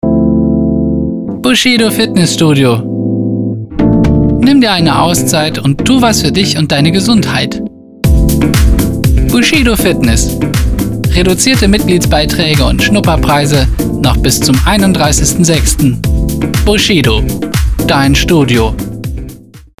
dynamic - professional - creative
deutsch
Sprechprobe: Werbung (Muttersprache):